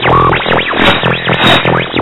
Radio Tune
Tuning radio receiver or radio dialing sound.